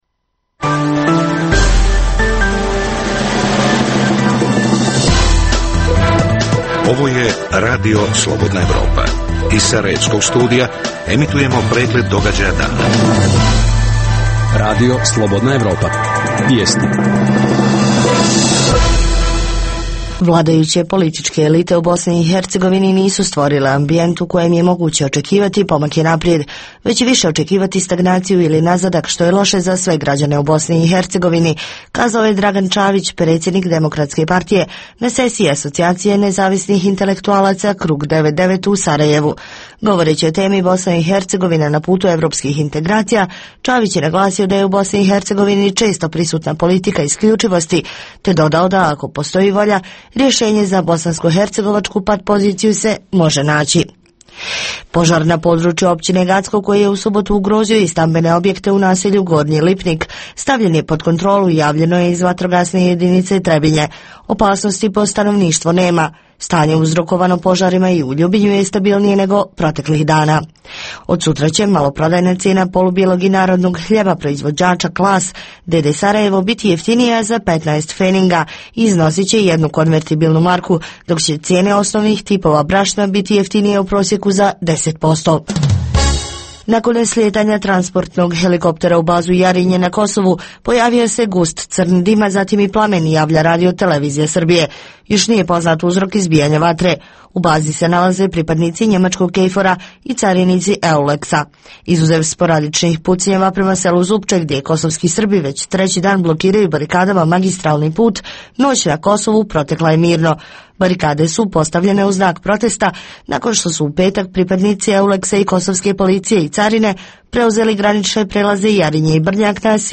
Intervju s Osmanom Topčagićem, ambasadorom BiH pri EU.